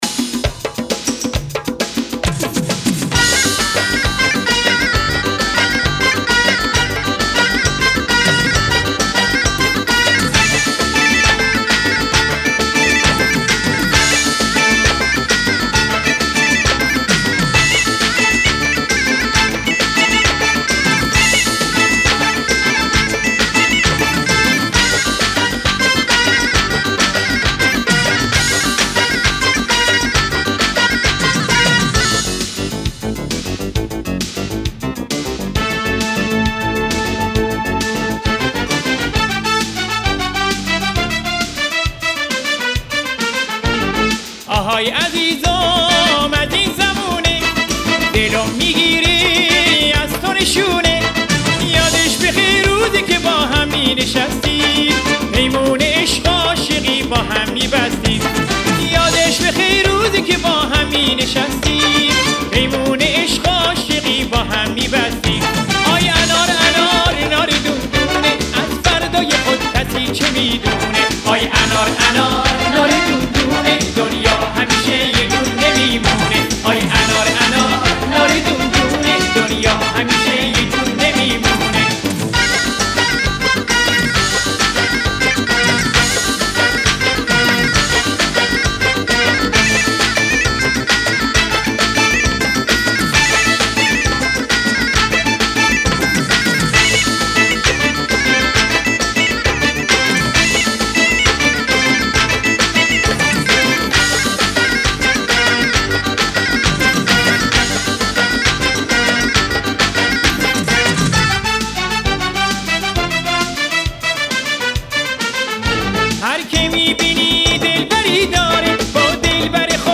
آهنگ شاد قدیمی